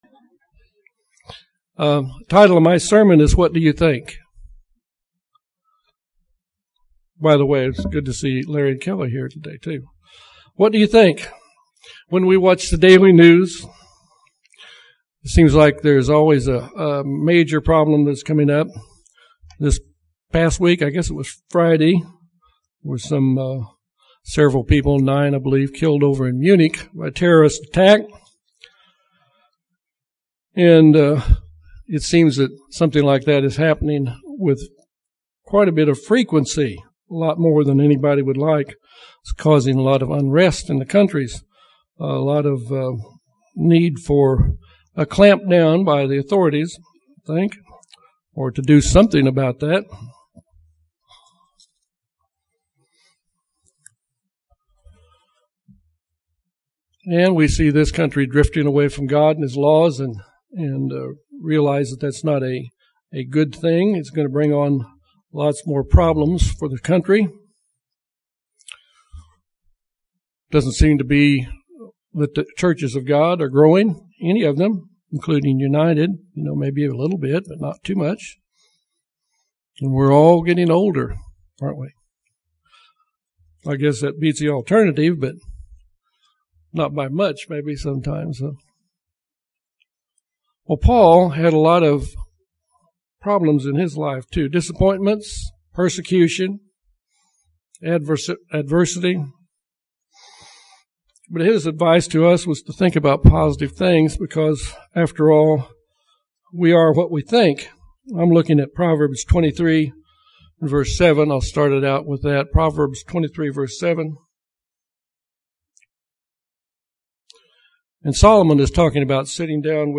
Despite his many trials, difficulties, and persecutions, Paul gave us some positive things to think and meditate about. This sermon elaborates on the eight things Paul said in Philippians 4:8 that we should meditate on.
Given in Little Rock, AR